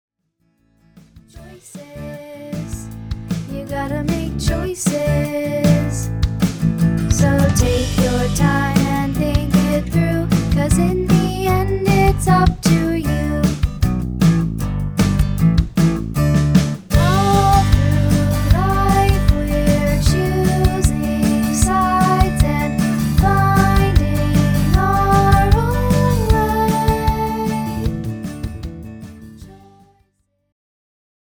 designed for young voices